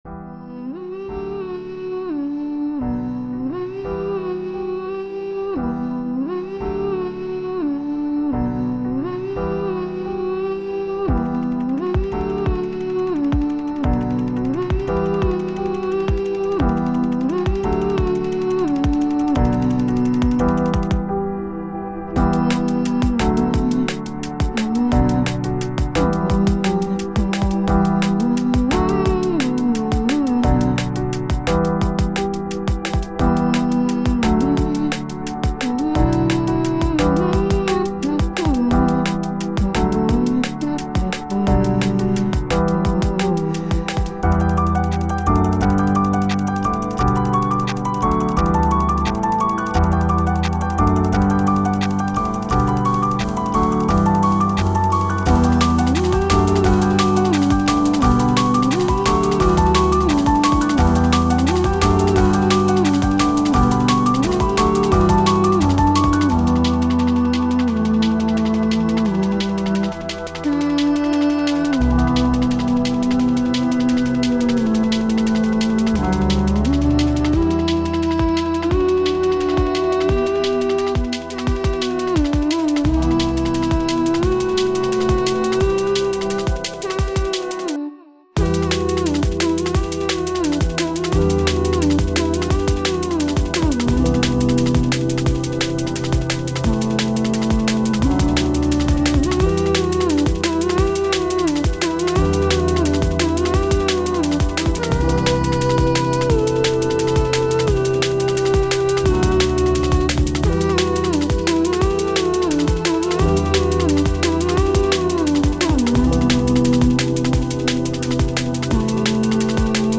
Ambient DnBに合成音声によるハミングを加えた楽曲。